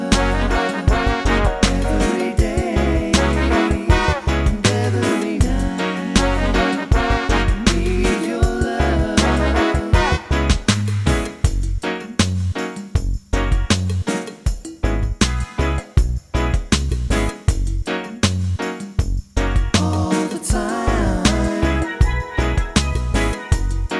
no Backing Vocals Reggae 3:52 Buy £1.50